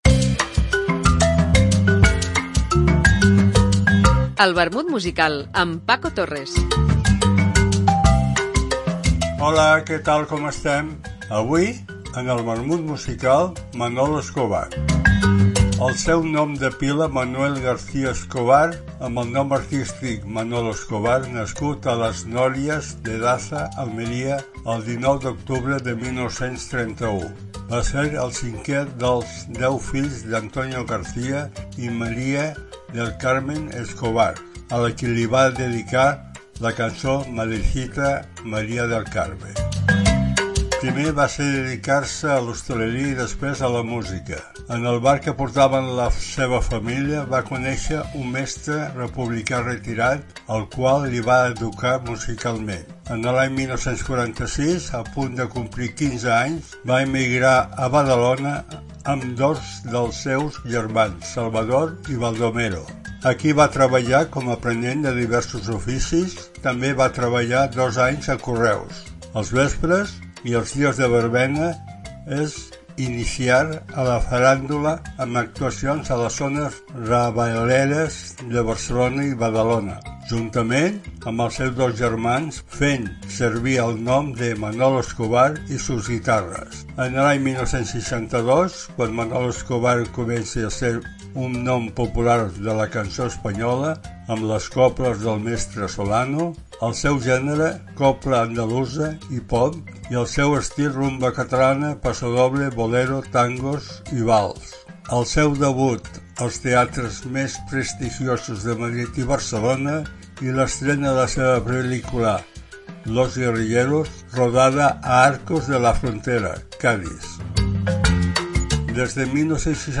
Una apunts biogràfics acompanyats per una cançó